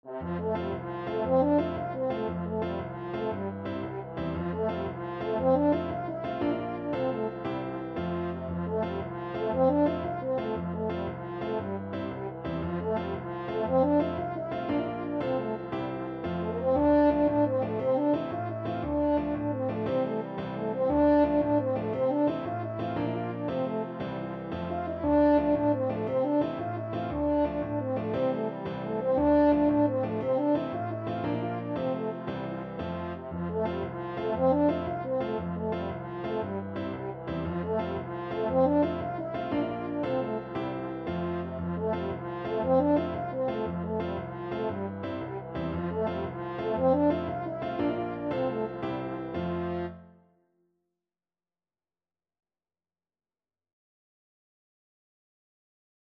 French Horn
Traditional Music of unknown author.
6/8 (View more 6/8 Music)
F major (Sounding Pitch) C major (French Horn in F) (View more F major Music for French Horn )
With energy .=c.116
Irish